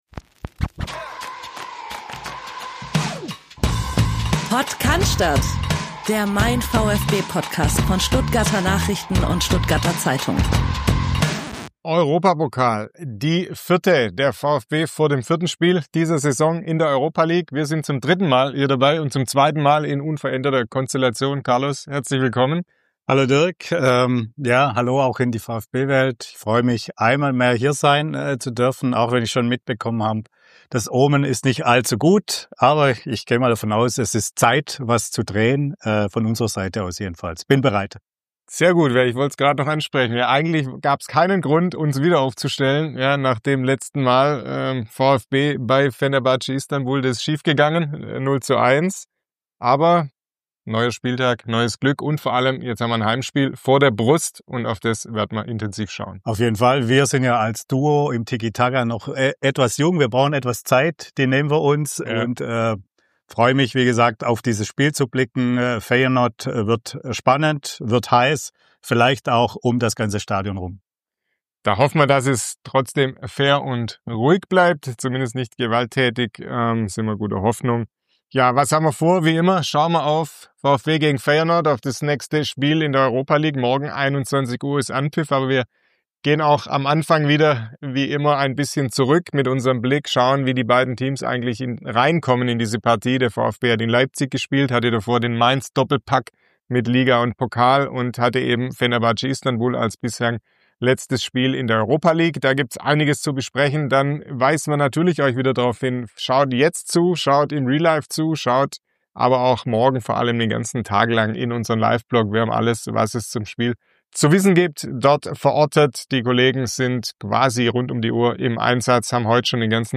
Es handelt sich um ein Audio-Re-Live des YouTube-Streams von MeinVfB.